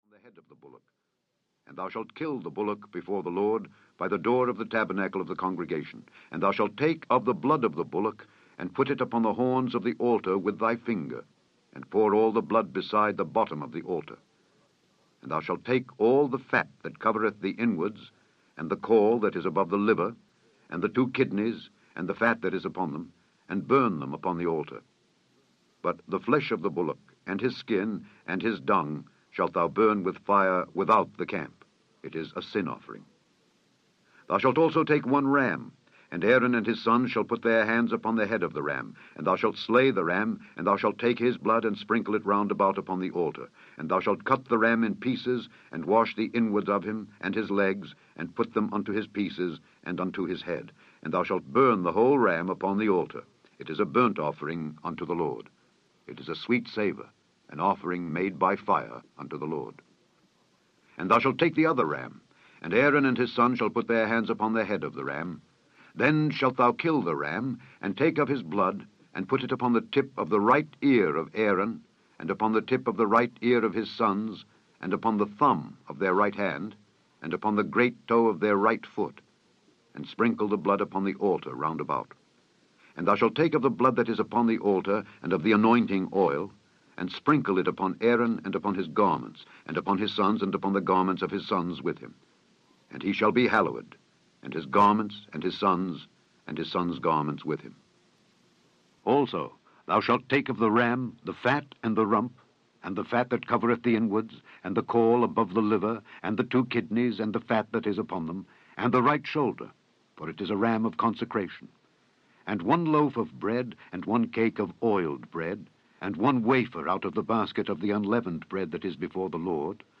The Holy Bible, narrated by Alexander Scourby, takes you on a journey through the Life of Christ. Experience the Life, the Passion and the Resurrection of Jesus Christ in this drama filled audio Bible!
He was undoubtedly known for his eloquent voice.
70 Hrs. – Unabridged
The Holy Bible KJV Scourby SAMPLE.mp3